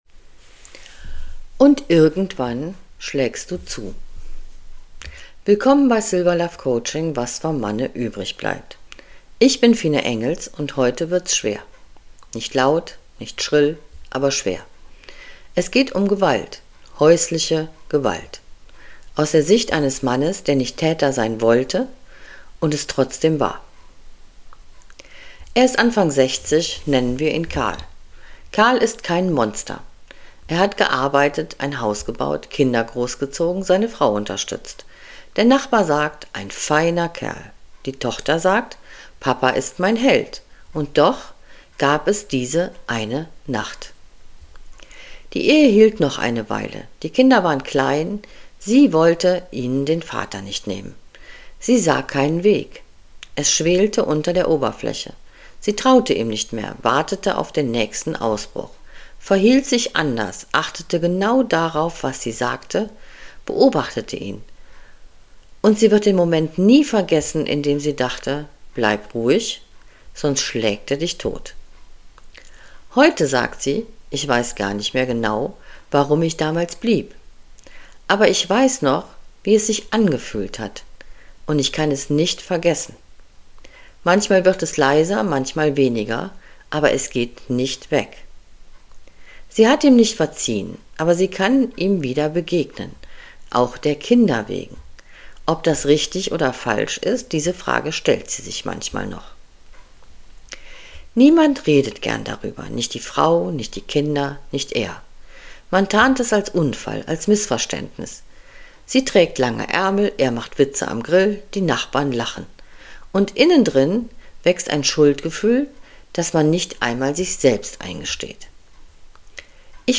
Eine leise, schwere Folge.